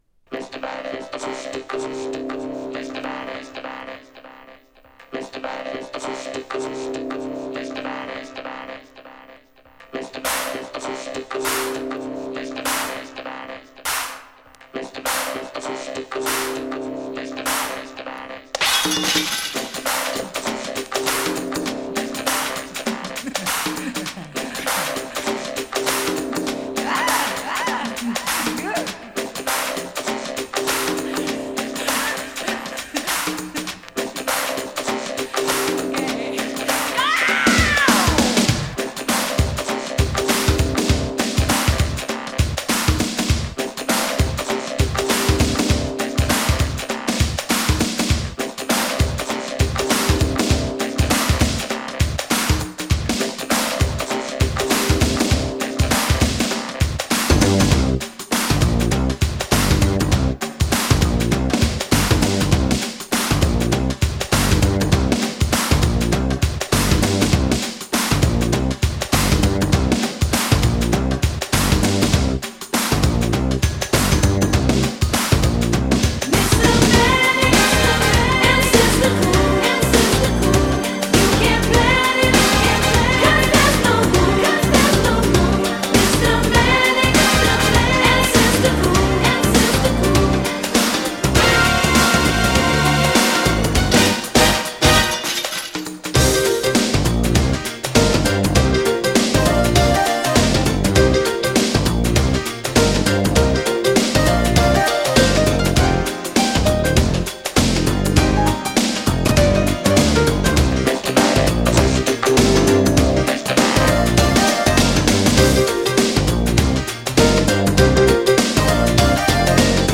URBAN FUNK〜DISCO CLASSIC !!